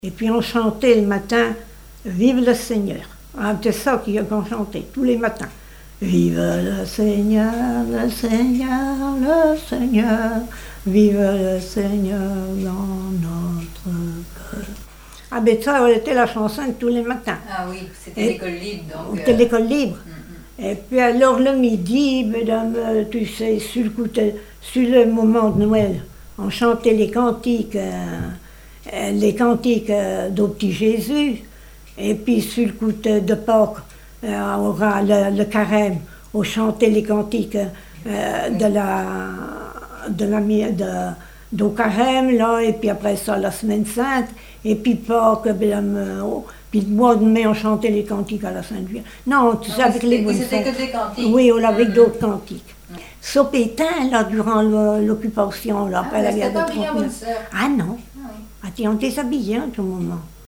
Témoignages et chansons
Catégorie Témoignage